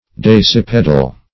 dasypaedal - definition of dasypaedal - synonyms, pronunciation, spelling from Free Dictionary Search Result for " dasypaedal" : The Collaborative International Dictionary of English v.0.48: Dasypaedal \Das`y*p[ae]"dal\, a. (Zool.)